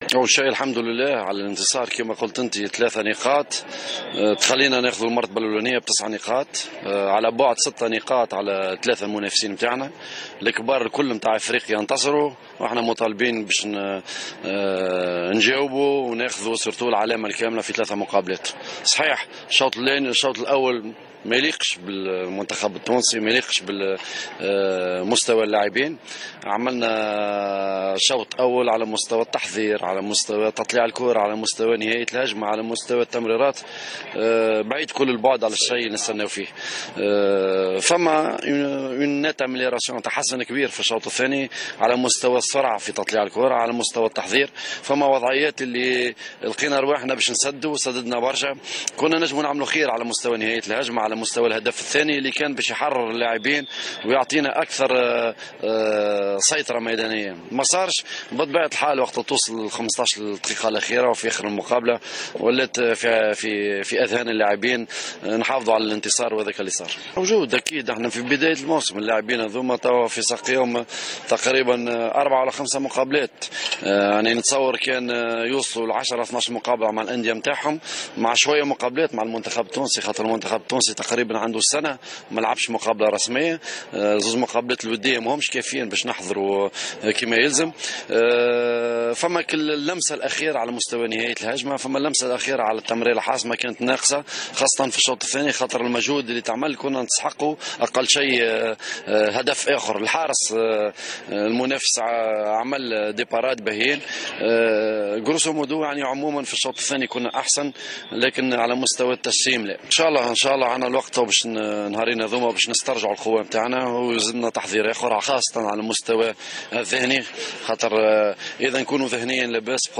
منذر الكبير : مدرب المنتخب الوطني